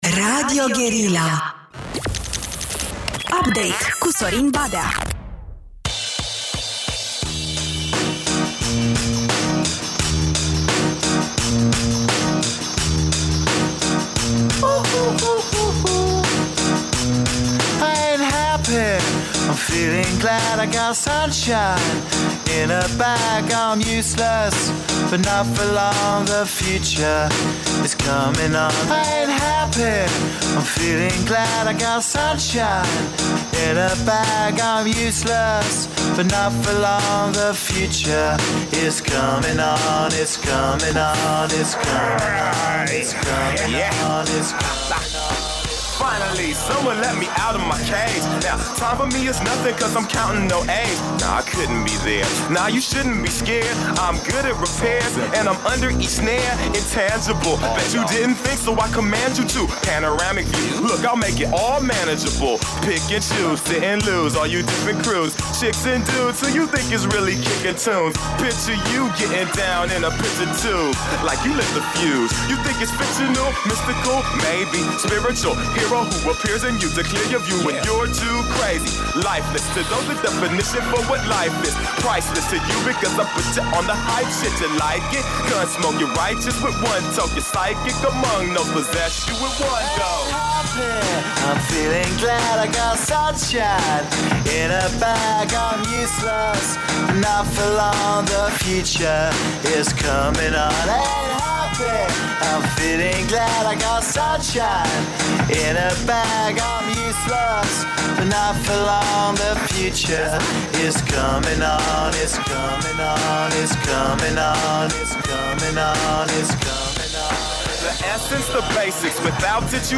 Invitați, discuții și muzică, adică un meniu obișnuit.